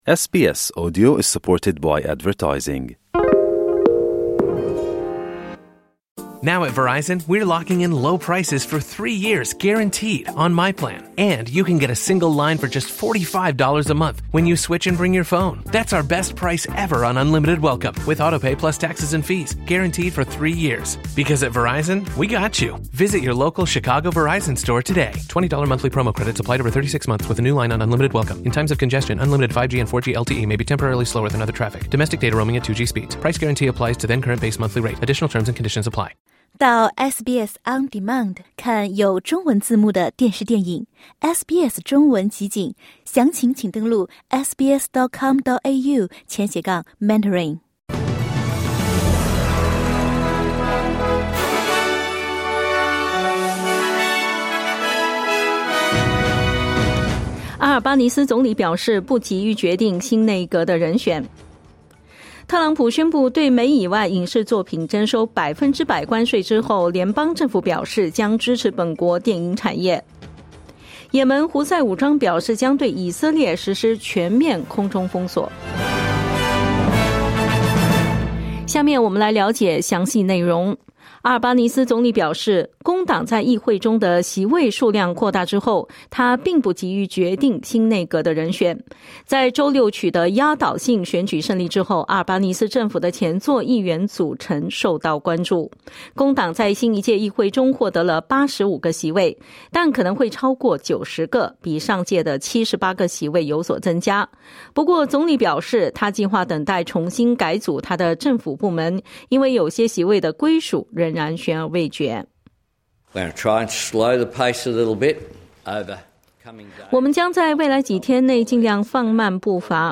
SBS早新闻（2025年5月6日）